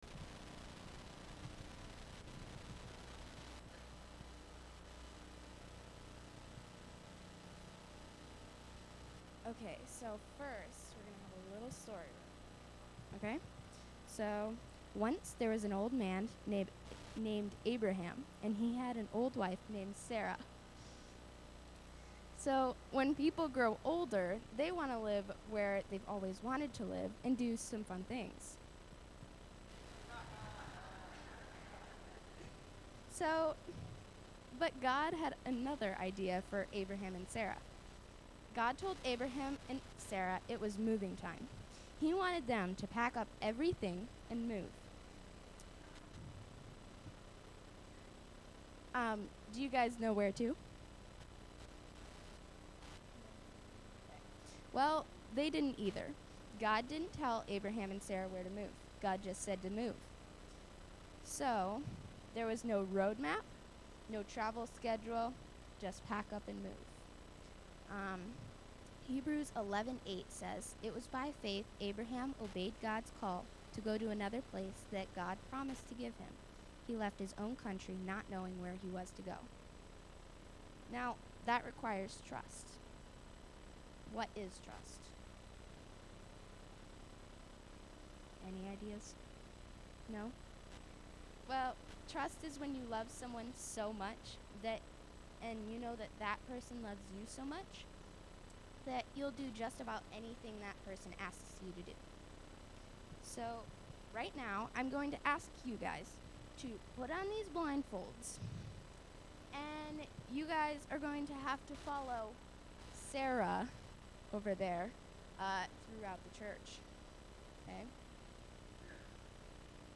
A message from the series "Guest Speakers."
Youth Sunday; The Youth Group from Bancroft Congregational Church performed most of the worship this morning.